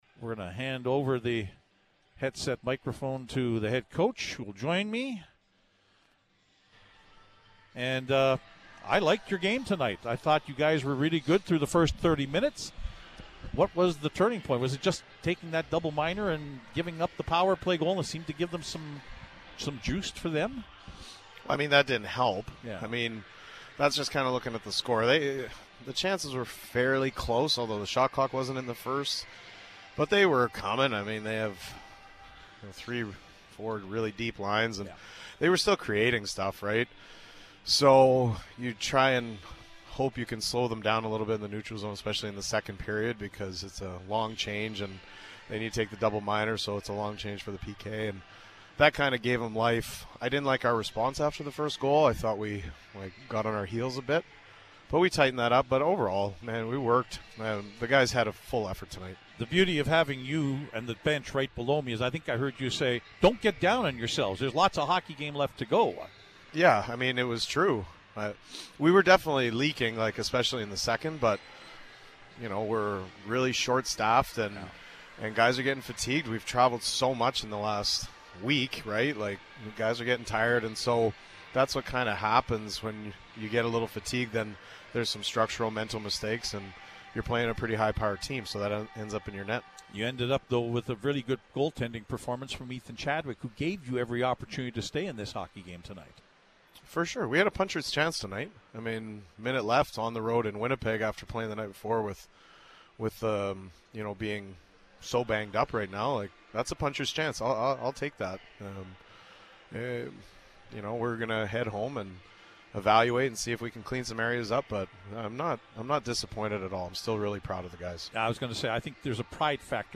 Post-Game Show interview